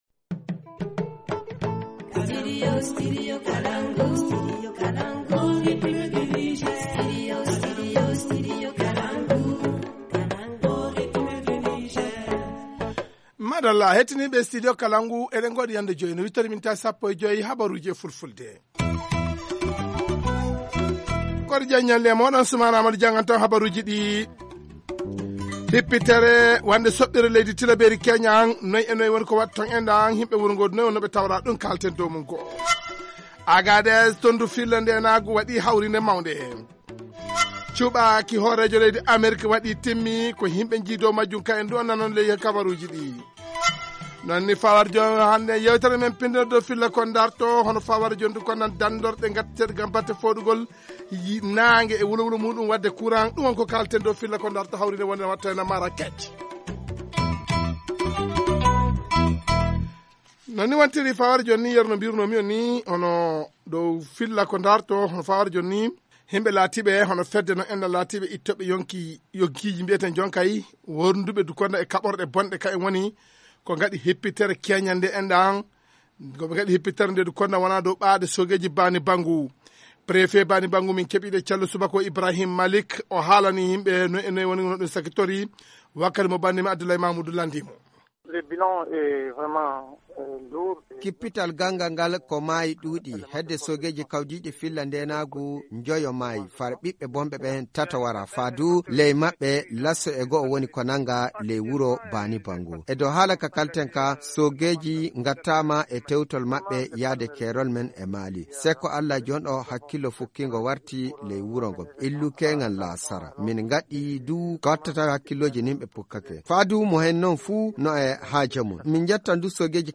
Journal en fulfuldé